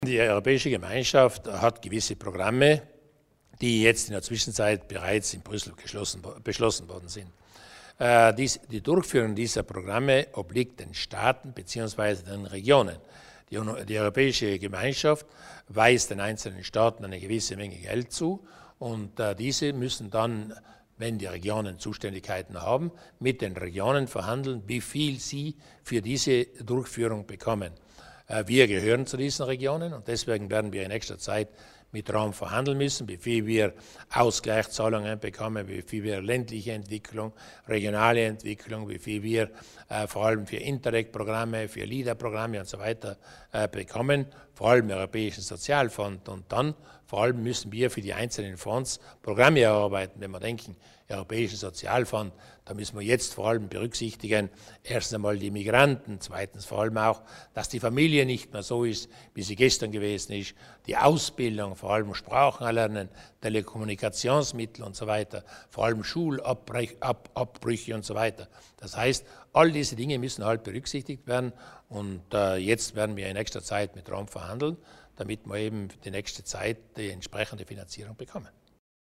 Landeshauptmann Durnwalder erläutert mögliche Zusammenarbeit auf europäischer Ebene